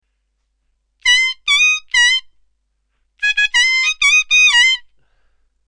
diatonic harmonica